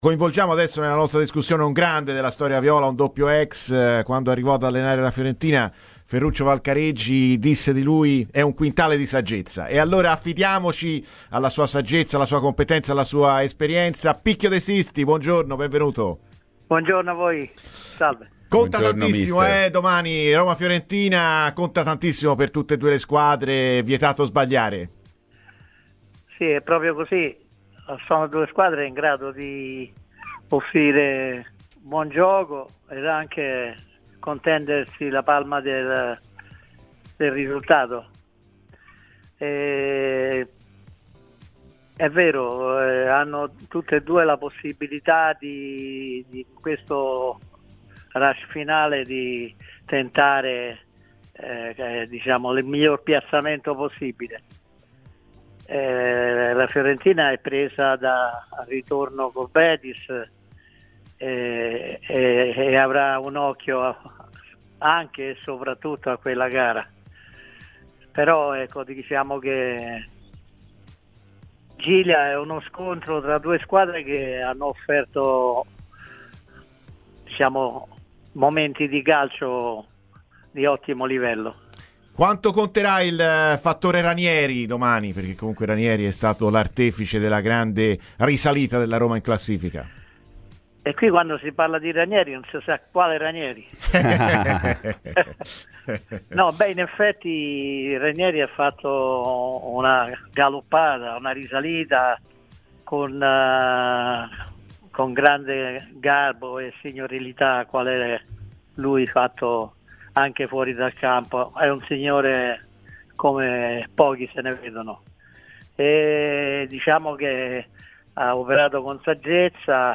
Interviste
intervenendo oggi a Radio FirenzeViola